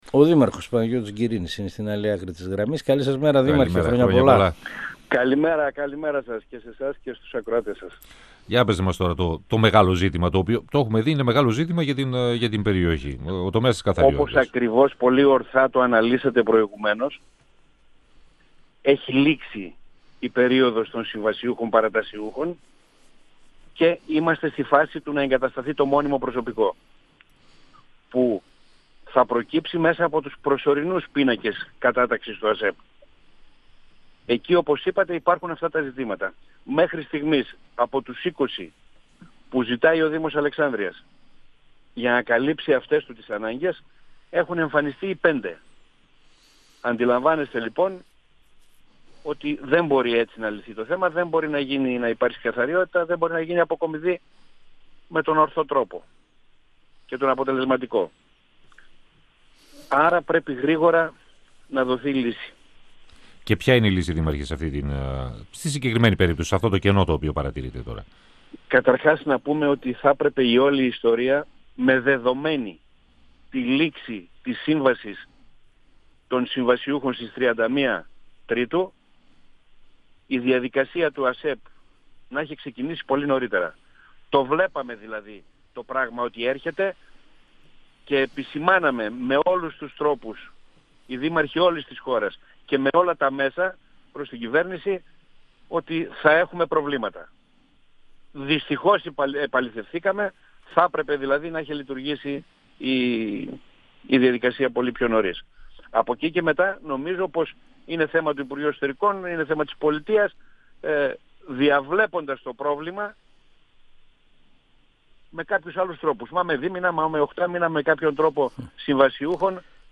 O δήμαρχος Αλεξάνδρειας, Παναγιώτης Γκυρίνης, στον 102FM του Ρ.Σ.Μ. της ΕΡΤ3
Συνέντευξη